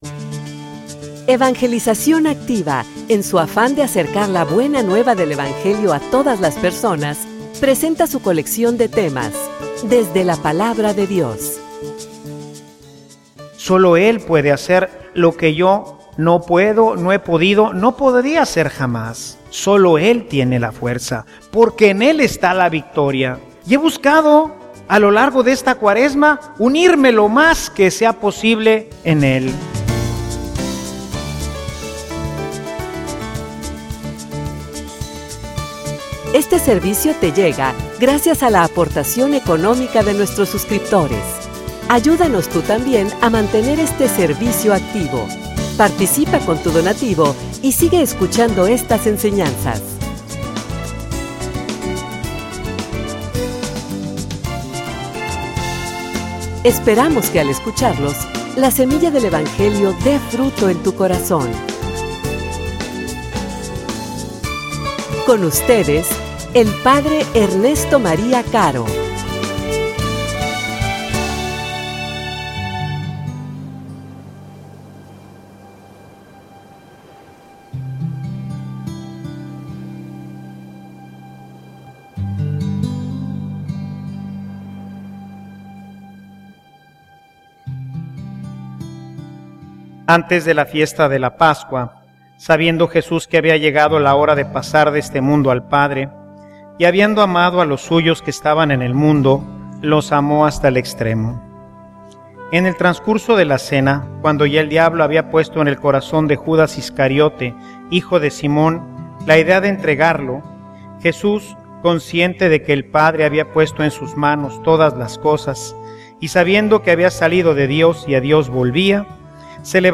homilia_El_pan_para_la_victoria.mp3